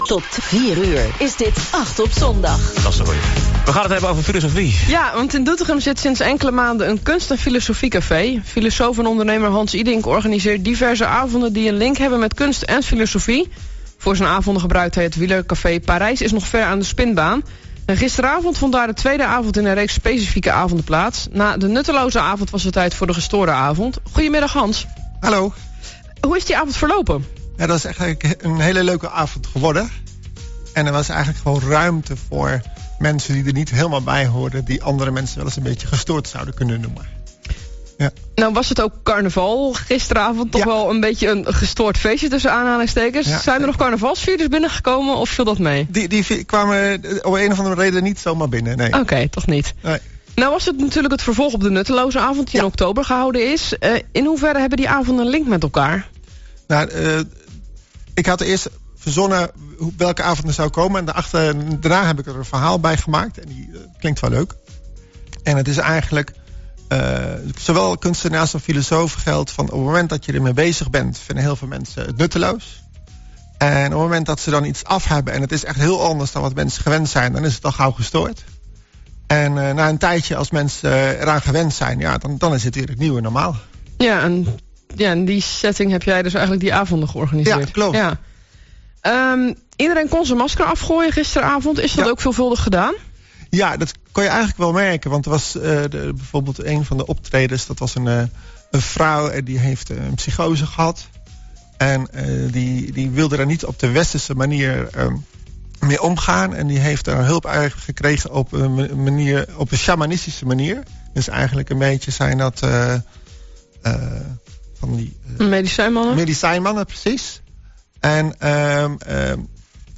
Interview Optimaal FM 11 februari 2018